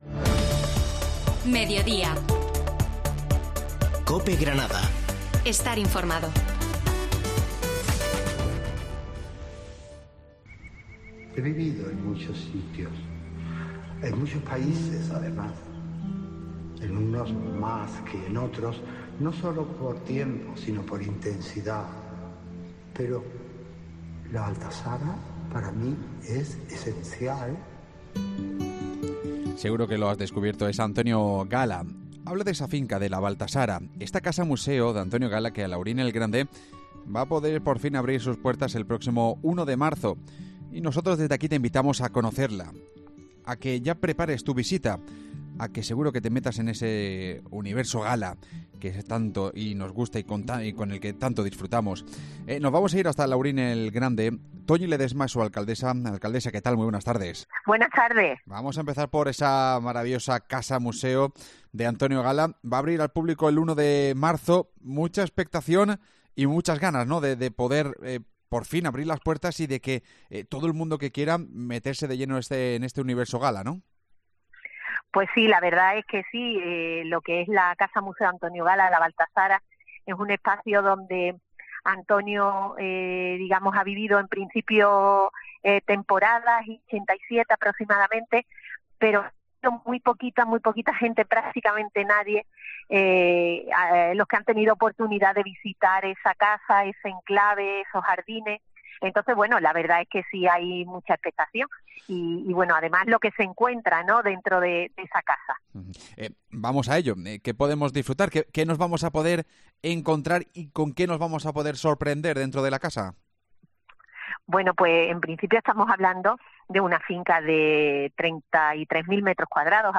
Hablamos con la alcaldesa del municipio malagueño, Toñi Ledesma, que además nos recomienda qué visitar de cara al puente de Andalucía